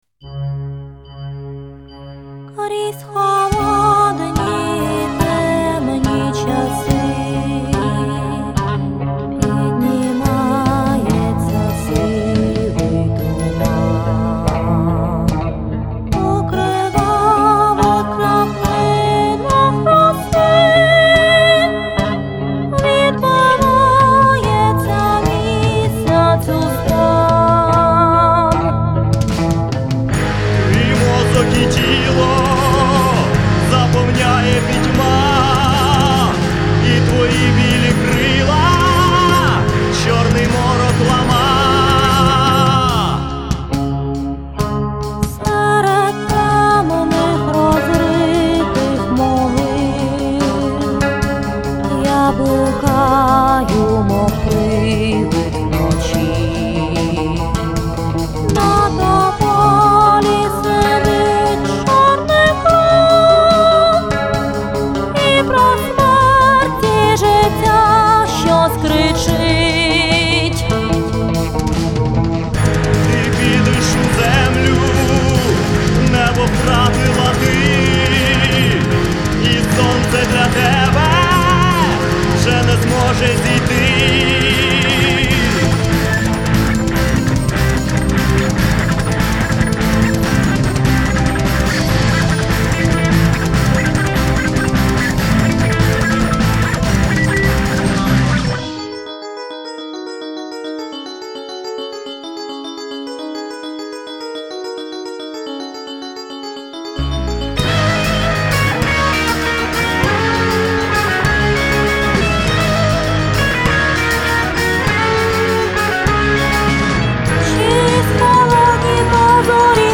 Тип: demo
Студія: своїми силами